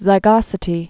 (zī-gŏsĭ-tē)